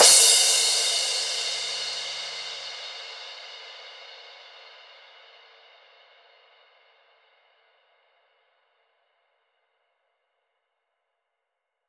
Crashes & Cymbals
edm-crash-06.wav